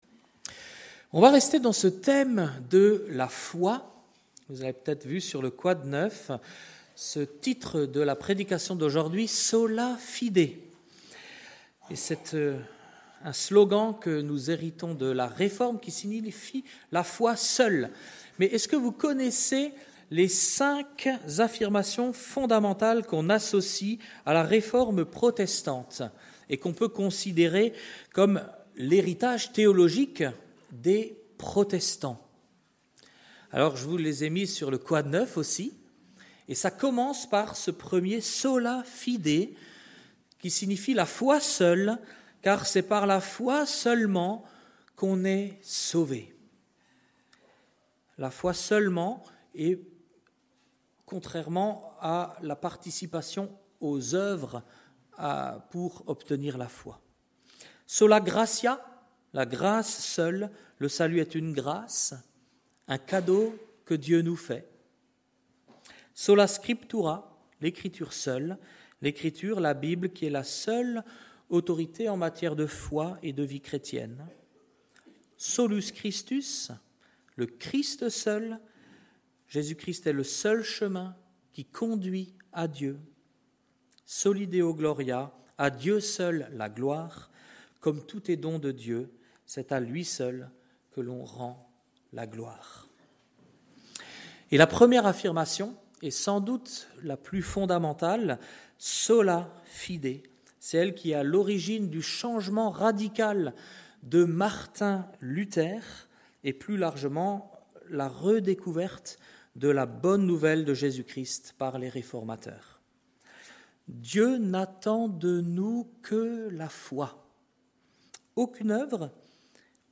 Culte du dimanche 26 avril 2026 – Église de La Bonne Nouvelle